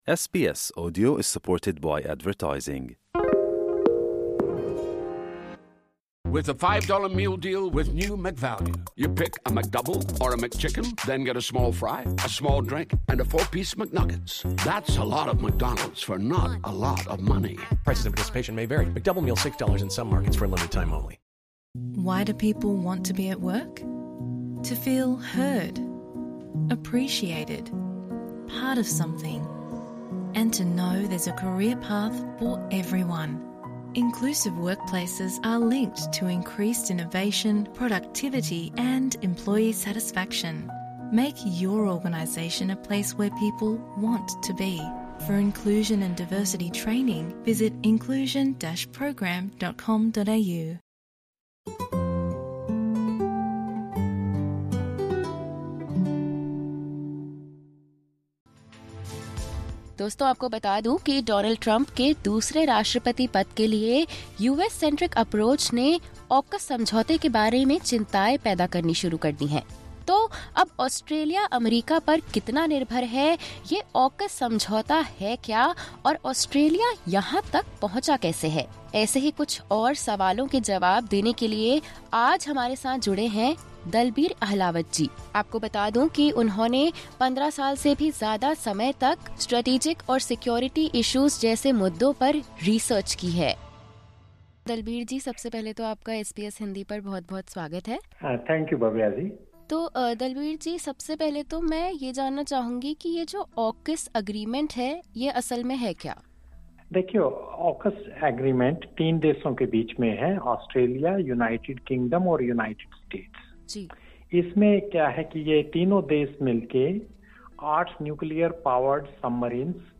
Disclaimer: The views expressed in this podcast episode are those of the interviewee and do not reflect the views of SBS Hindi.